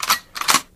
Gun_Cock_1.wav